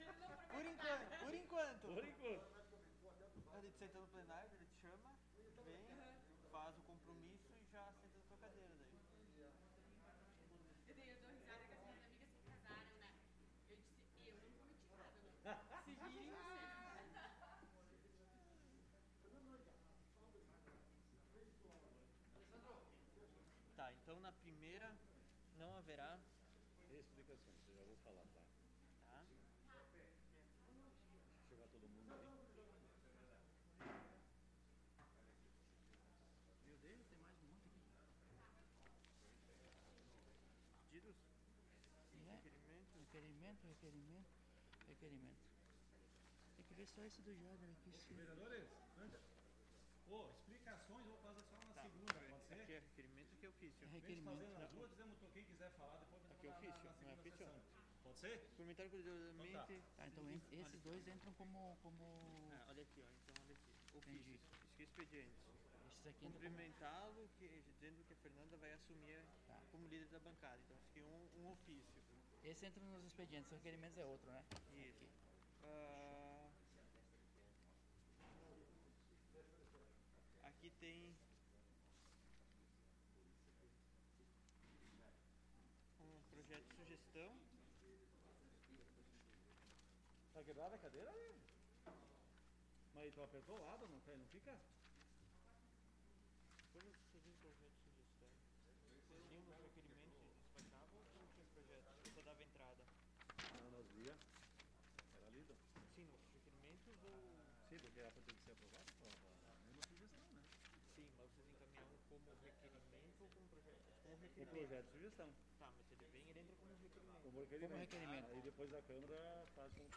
Sessão Ordinária do dia 11 de Janeiro de 2021 - Sessão 01